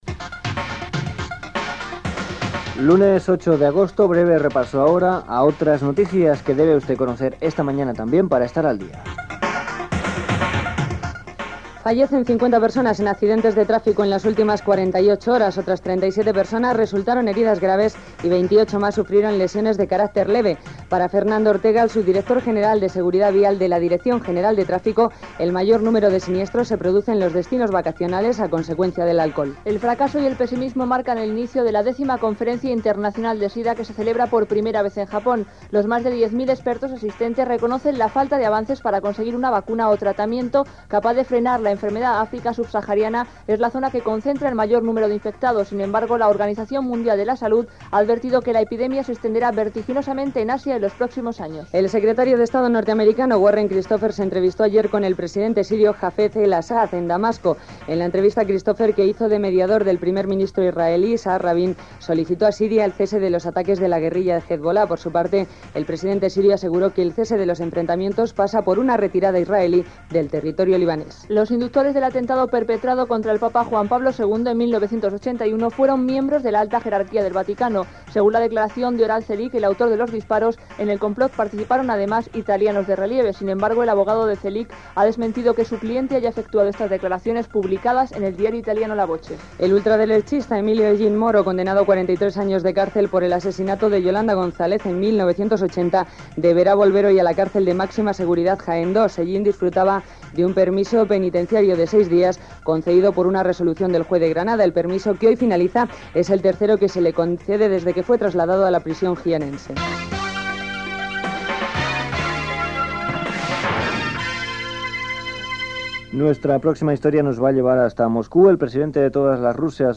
Titulars: víctimes de trànsit, X Conferència Internacional sobre la SIDA, visita del secretari d'estat dels EE.UU. a Damasc, etc. El presidengt de Rússia es construeix un iot de luxe. Publicitat. Indicatiu del programa.
Informatiu